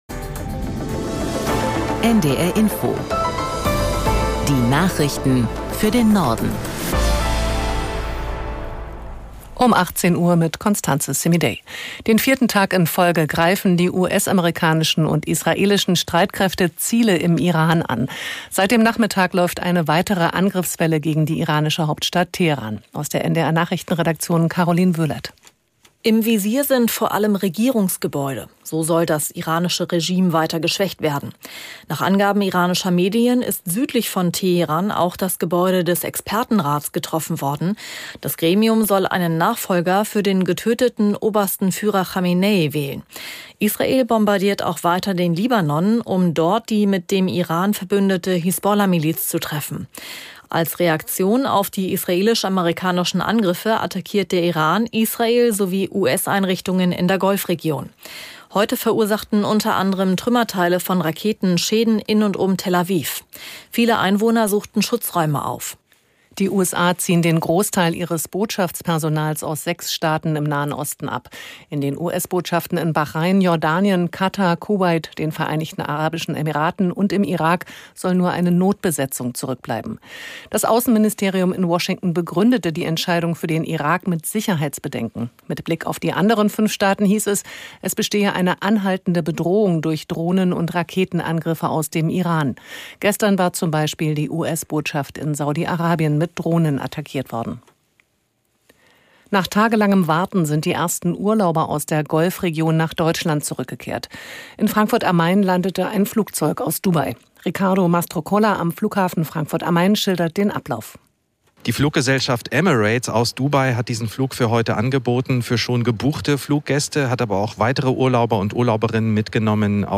NDR Info Nachrichten